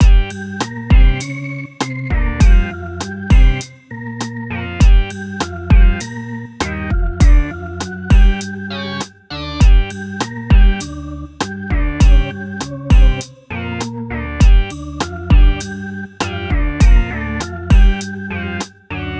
Palavras-chave: Foley